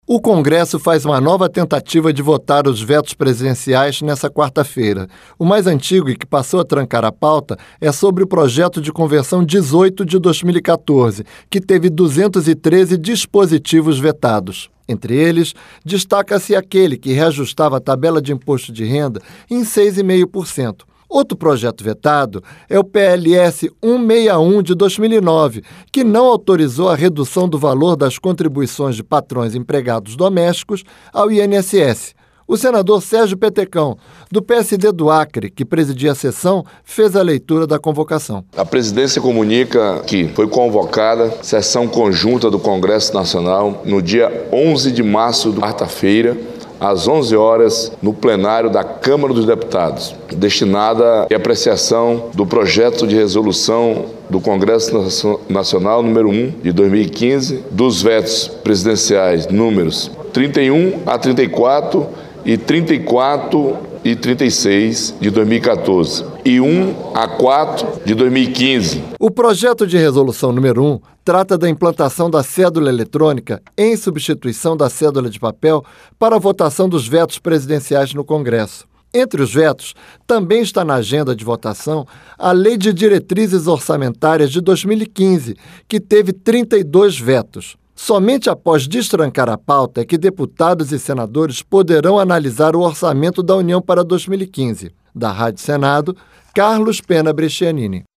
O senador Sérgio Petecão, do PSD do Acre, que presidia a sessão fez a leitura da convocação: